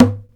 SHEKO MUTE A.WAV